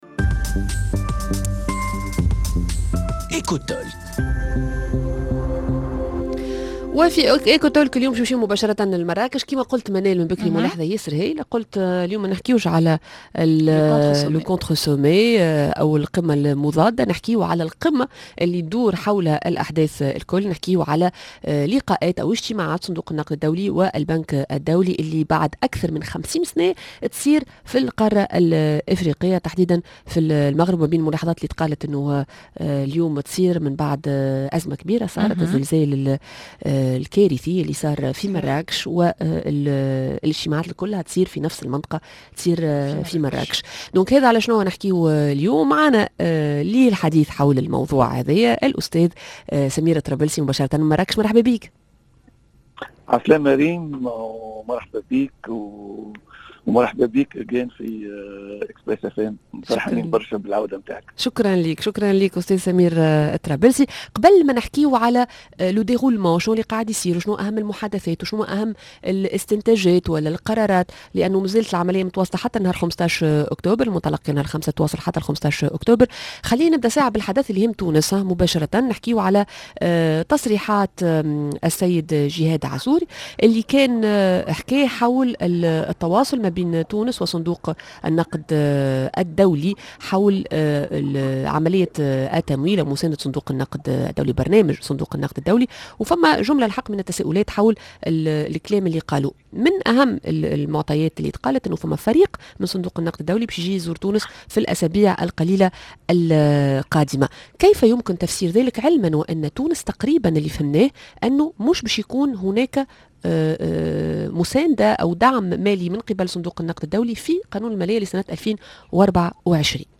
مباشرة من الاجتماعات السنوية للبنك و صندوق النقد الدوليين